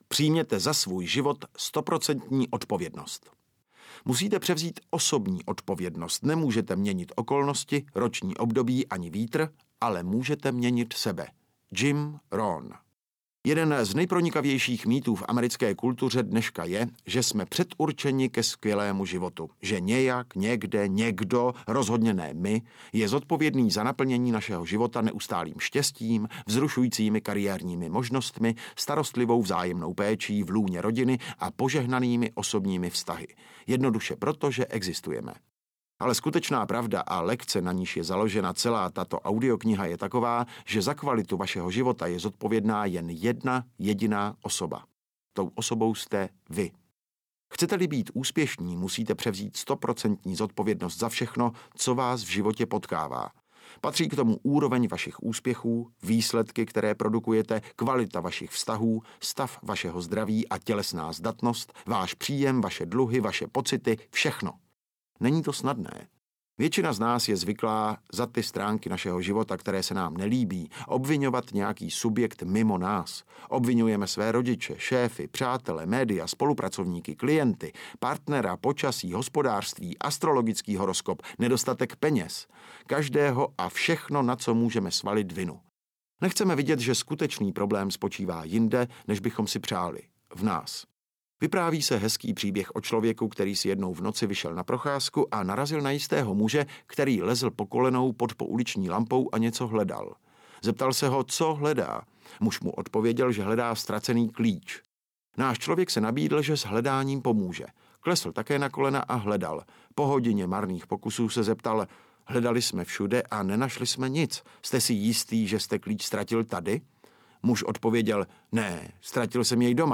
Audiokniha Pravidla úspěchu - Jack Canfield | ProgresGuru